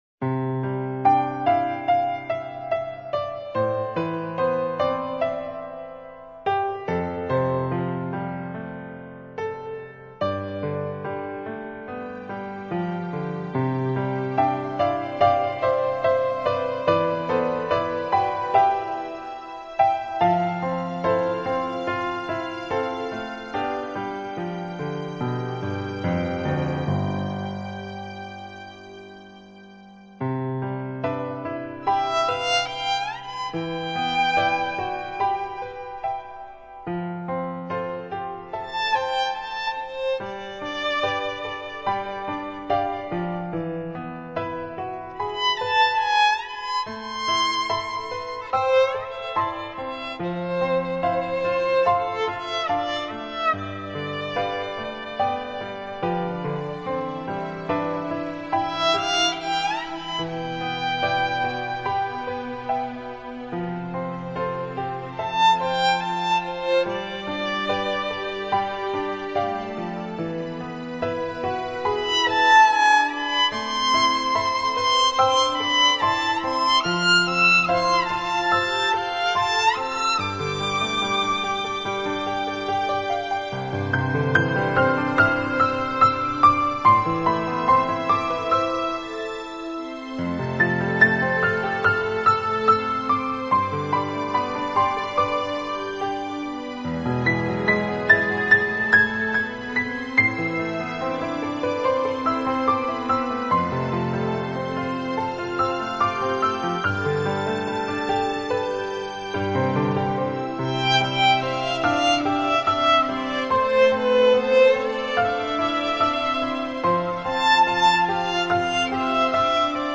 听，键盘上黑色精灵与白色天使，悠然舞动藏在夜风里的秘密心曲